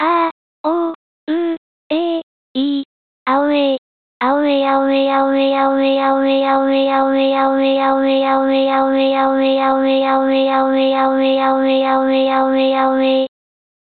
ゆっくりと「アーオーウーエーイー」　「アーオーウーエーイー」と繰り返していると・・・
アオウエイ　　（音声読み上げソフト）
女声-1）
hz-aouei-fem-1.mp3